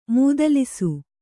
♪ mūdalisu